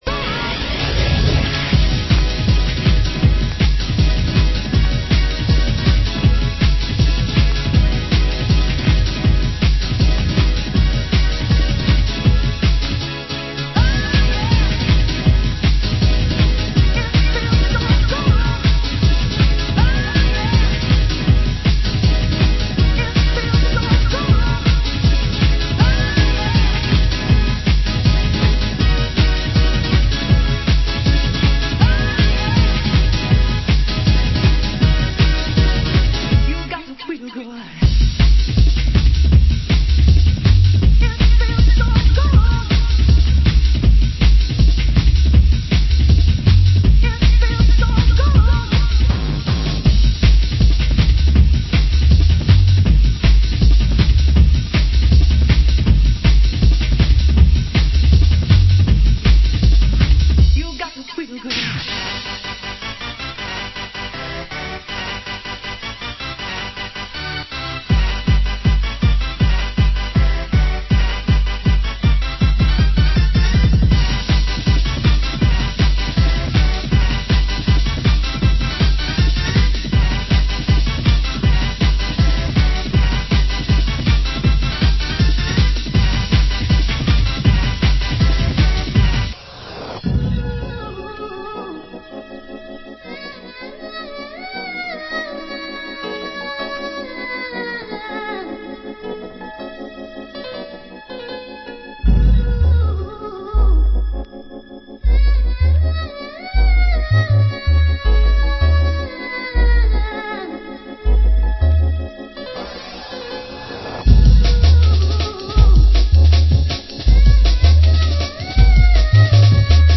Genre Hardcore